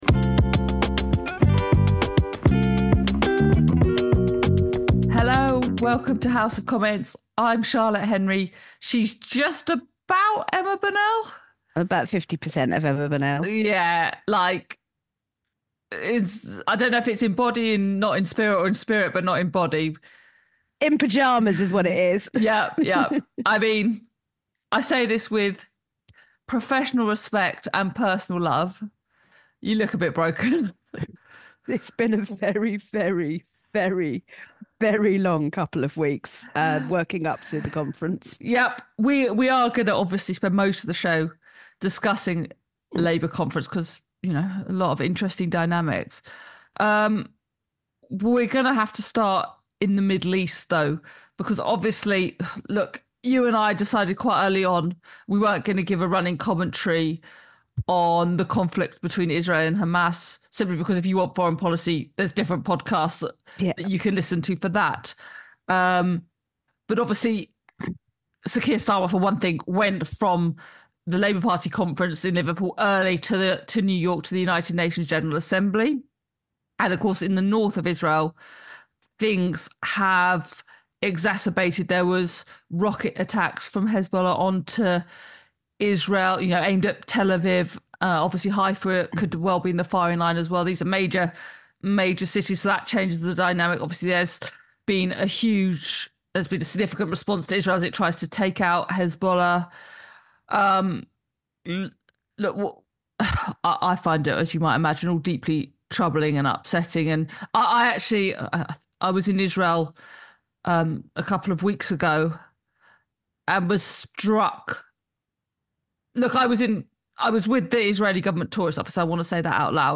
*Applause*. They debate if Sir Keir Starmer and Rachel Reeves were able to lift the gloom. They also analyse the ongoing war in the Middle East.